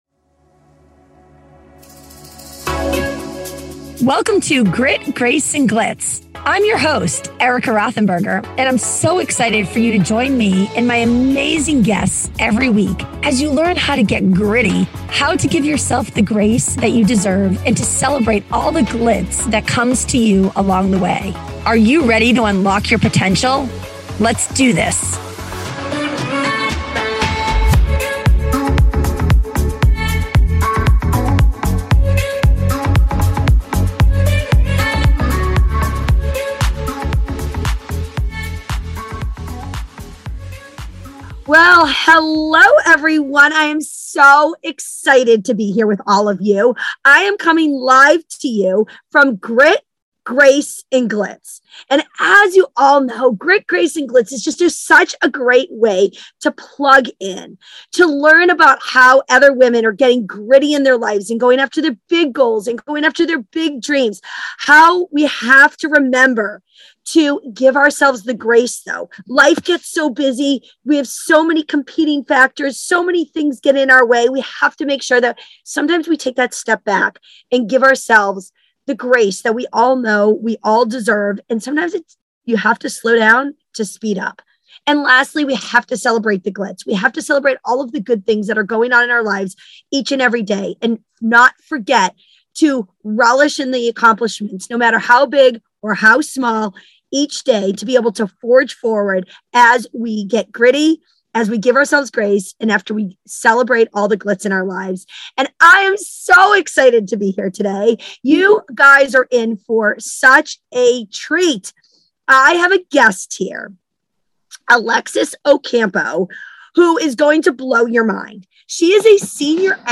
They have a candid conversation around the mental checklist so many of us carry.